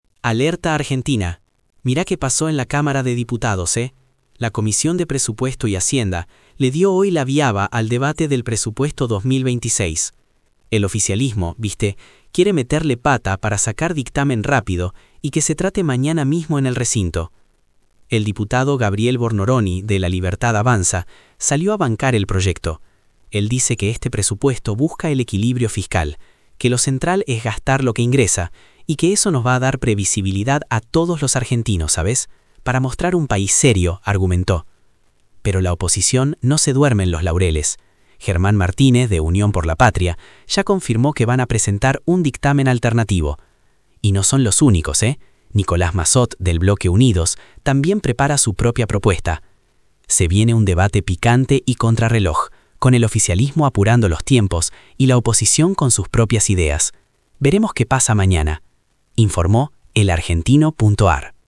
— 🎙 Resumen de audio generado por IA.